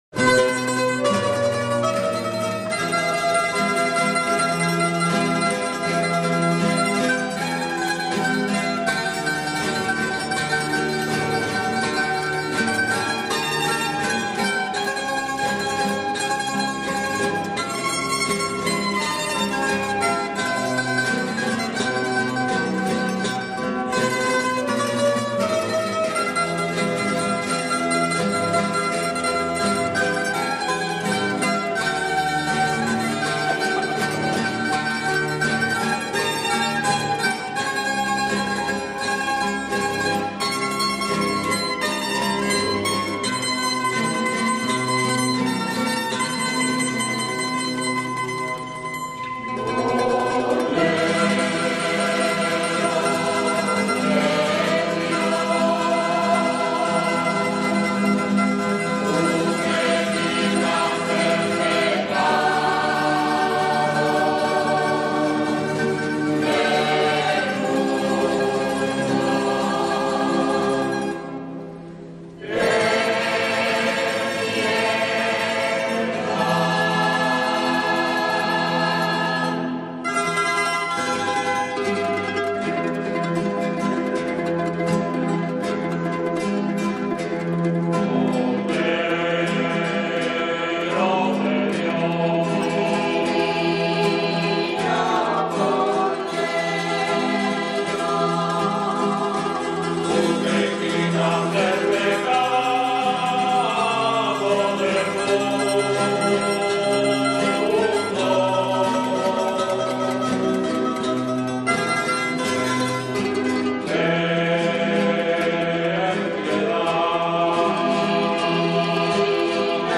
El magnífico Coro Amistad estuvo presente el pasado lunes 28 de Febrero de 2005 en la Solemne Función Votiva en Honor al Santísimo Cristo de Santiago, Patrono de Utrera, que se celebró en la Parroquia de Santiago a las 12:00 horas.
He aquí uno de los temas a coro que interpretaron para el deleite de todos los asistentes.